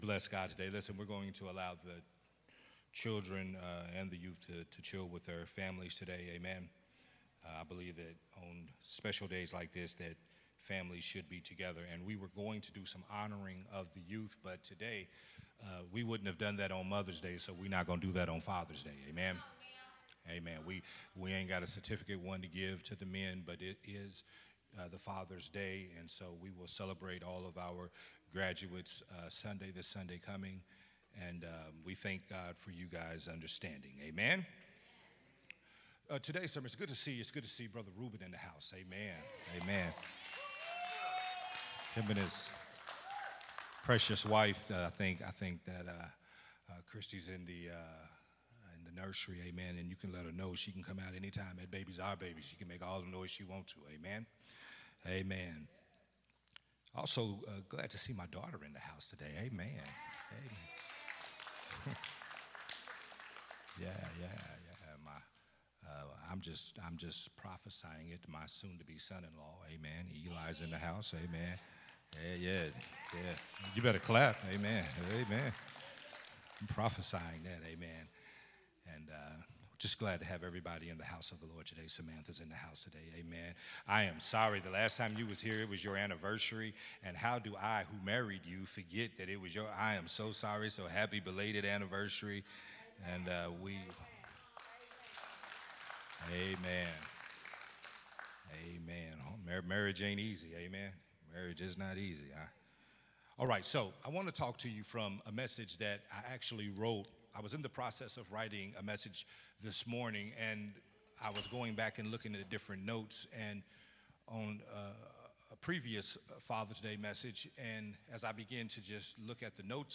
recorded at Unity Worship Center on June 16th, 2024.
Sunday Morning Worship Service